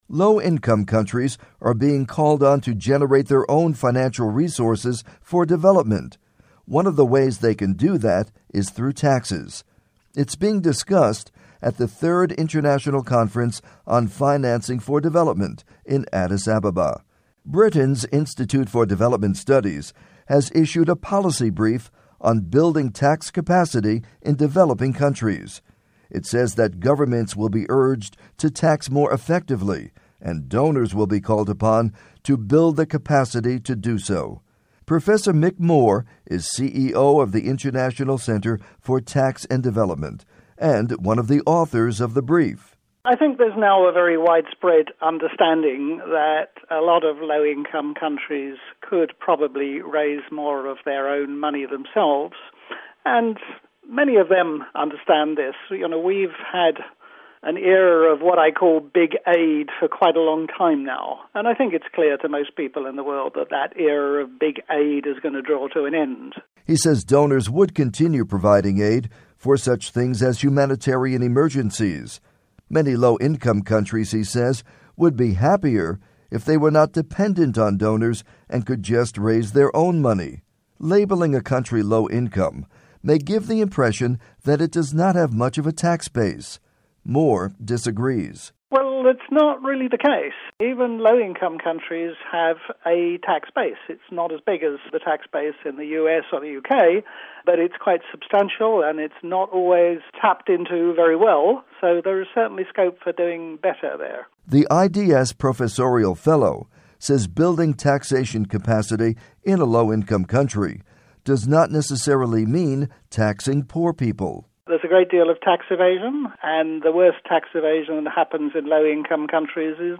report on taxes and development